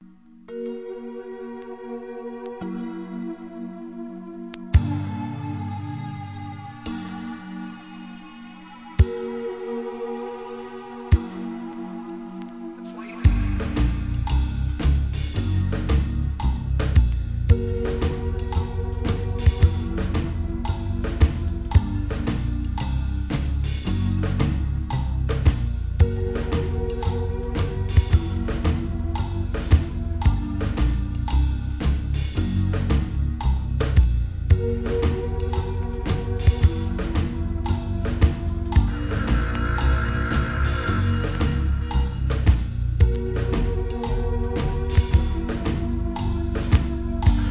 アンビエント、ブレイクビーツ